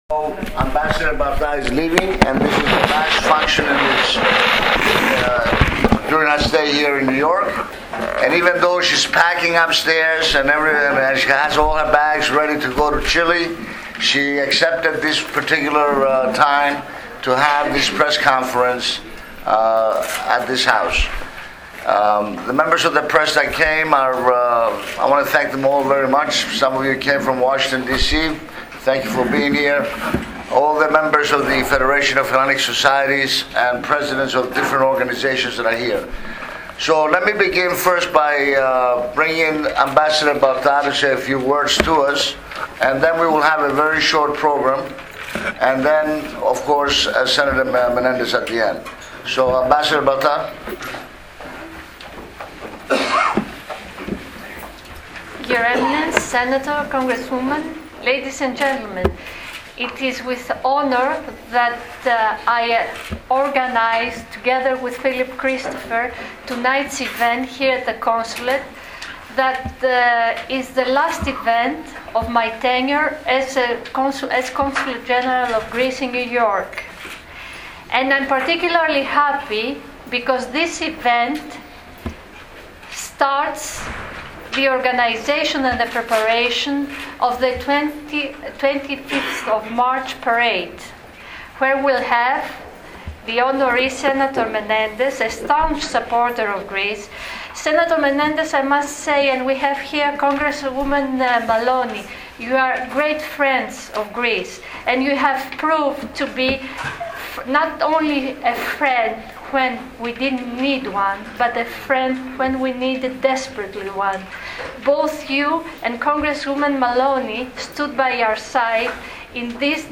ΑΚΟΥΣΤΕ ΕΔΩ lIVE ΟΛΟΚΛΗΡΗ ΤΗΝ ΕΚΔΗΛΩΣΗ ΤΗΣ ΕΠΙΣΗΜΗΣ ΤΕΛΕΤΗΣ ΑΠΟΔΟΧΗΣ ΤΗΣ ΥΠΟΨΗΦΙΟΤΗΤΑΣ ΤΟΥ ΓΕΡΟΥΣΙΑΣΤΗ BOB MENENDEZ ΣΤΟ ΓΕΝΙΚΟ ΠΡΟΞΕΝΕΙΟ ...